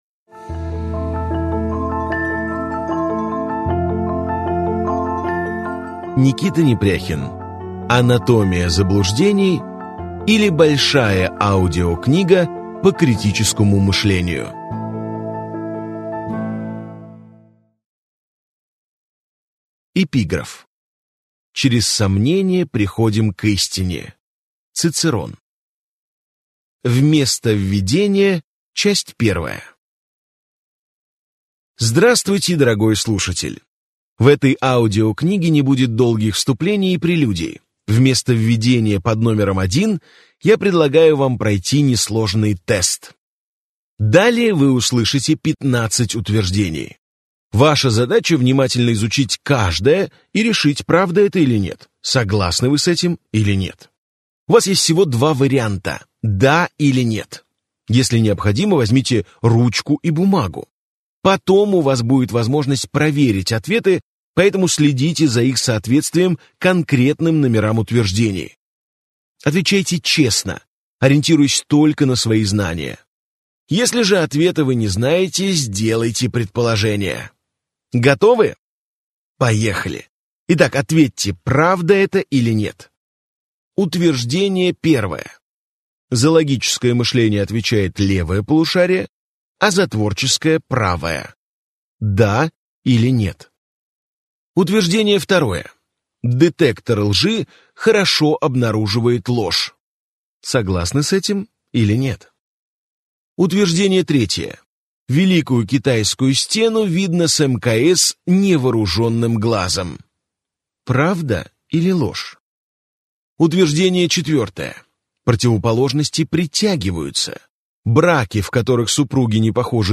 Аудиокнига Анатомия заблуждений. Большая книга по критическому мышлению | Библиотека аудиокниг